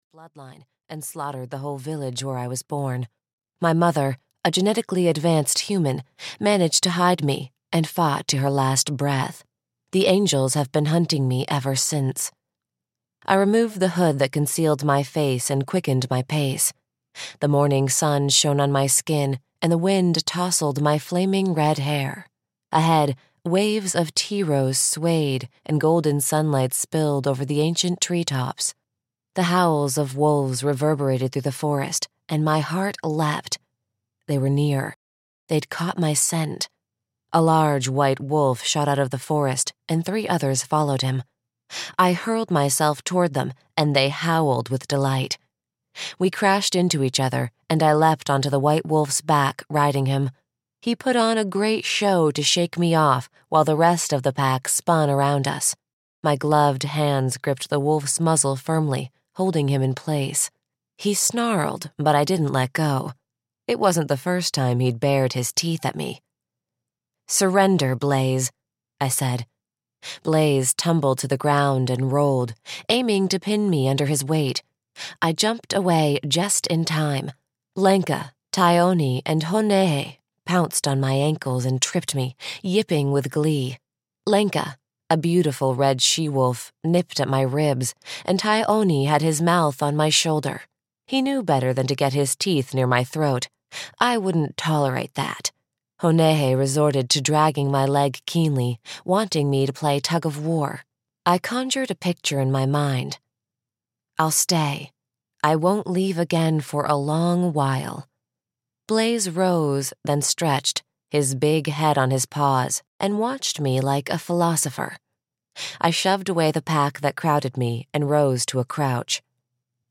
The Dragonian’s Witch (EN) audiokniha
Ukázka z knihy